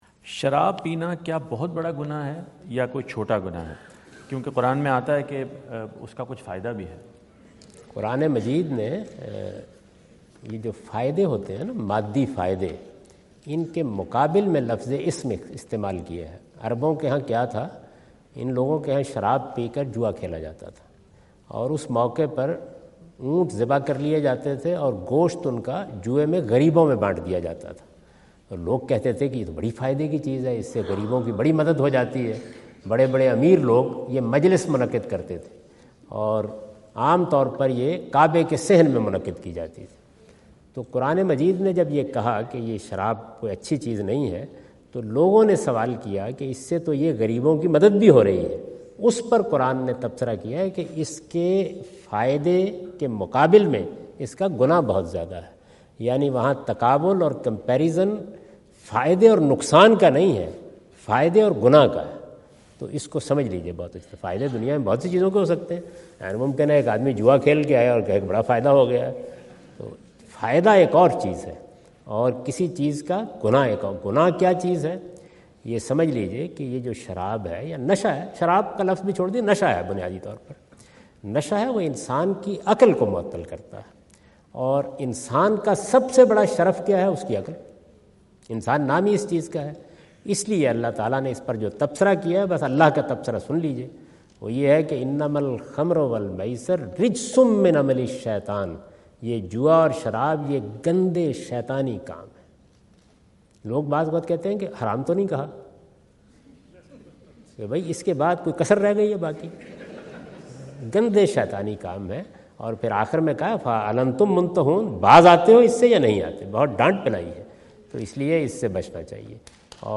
Javed Ahmad Ghamidi answer the question about "Is Consumption of Alcohol a Sin in Islam?" asked at North Brunswick High School, New Jersey on September 29,2017.